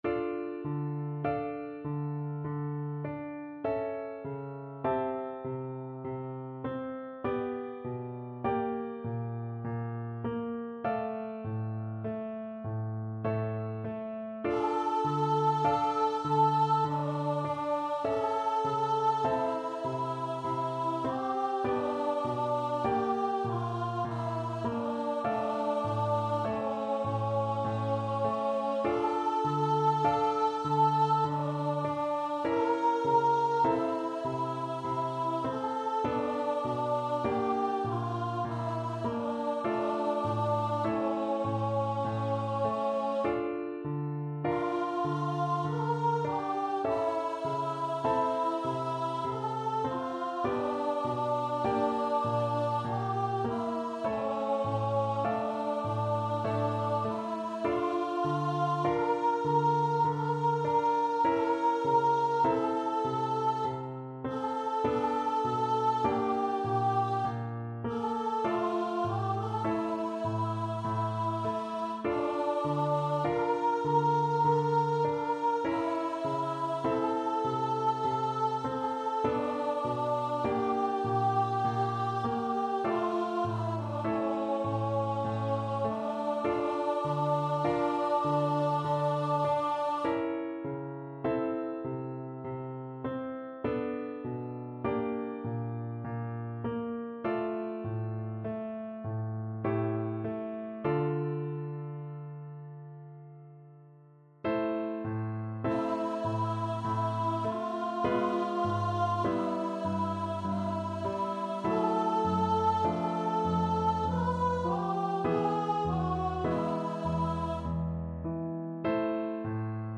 Voice
D minor (Sounding Pitch) (View more D minor Music for Voice )
~ = 100 Adagio =c.50
6/4 (View more 6/4 Music)
Classical (View more Classical Voice Music)